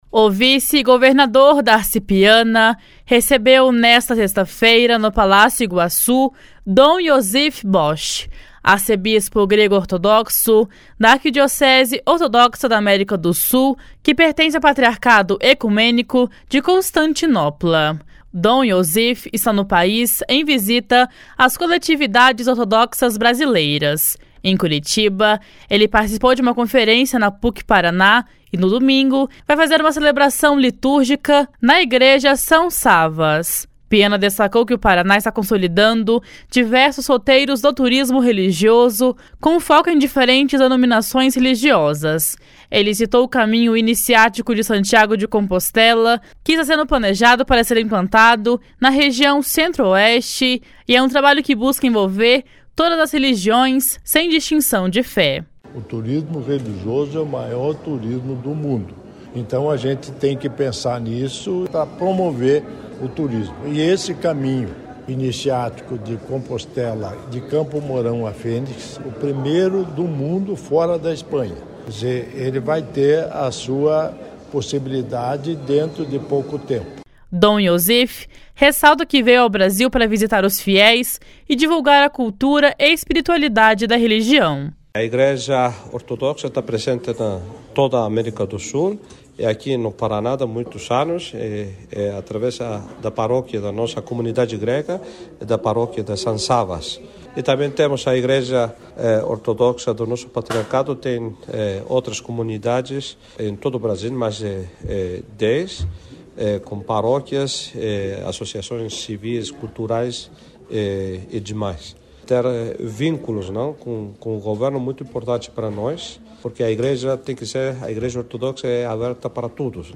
// SONORA DARCI PIANA //
Dom Iosif ressalta que veio ao Brasil para visitar os fiéis e divulgar a cultura e espiritualidade da religião. // SONORA DOM IOSIF //